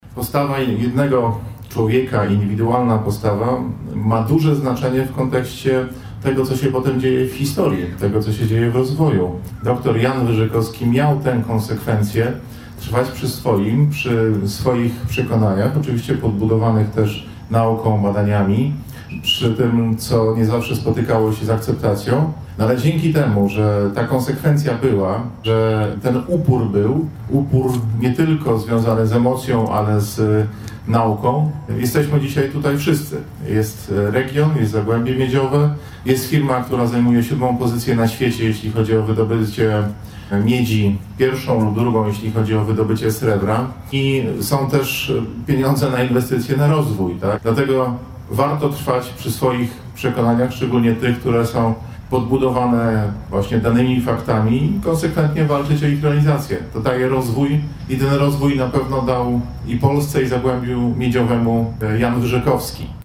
Z tej okazji przy pomniku Jana Wyżykowskiego w Lubinie odbyły się uroczystości upamiętniające tamto wydarzenie.